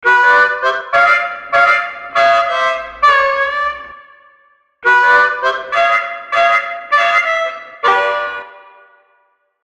标签： 100 bpm Blues Loops Harmonica Loops 1.62 MB wav Key : Unknown
声道立体声